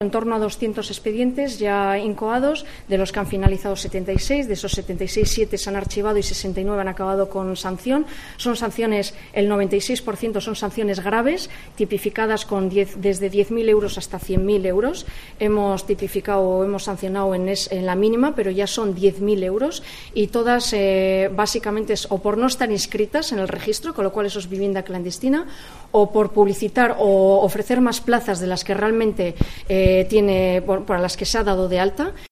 Sonia Pérez, consejera de turismo y comercio